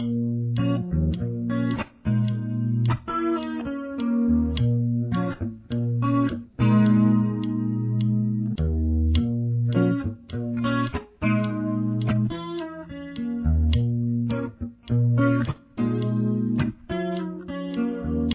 Main Theme
The tempo for the theme is 104.